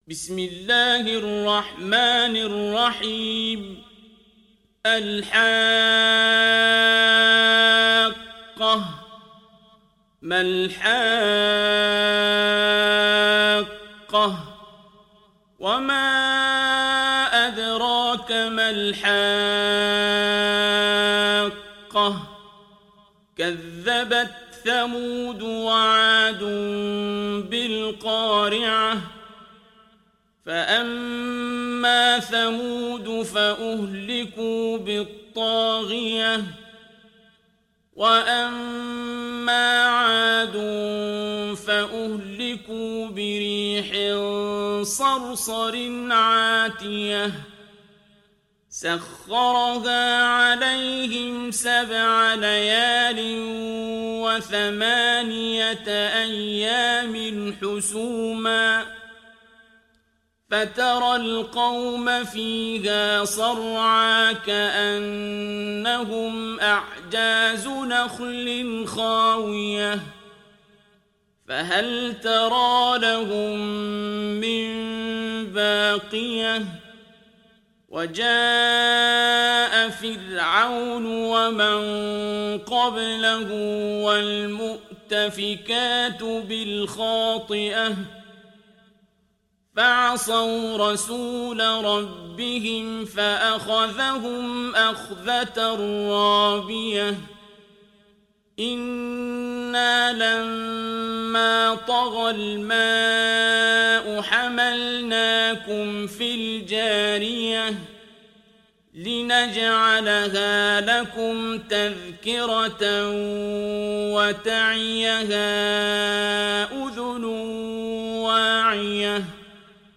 Surat Al Haqqah Download mp3 Abdul Basit Abd Alsamad Riwayat Hafs dari Asim, Download Quran dan mendengarkan mp3 tautan langsung penuh